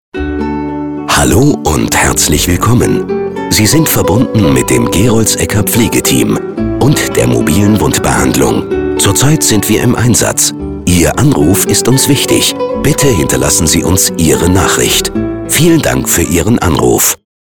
Telefonansage Pflegeteam